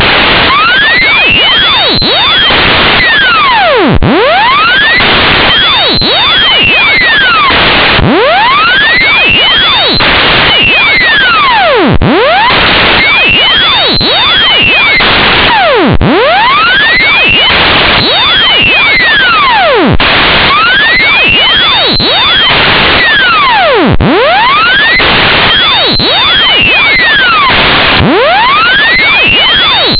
Enjoy, but not loudly.
Sample Rate: 8200 Hz
Channels: 1 (mono)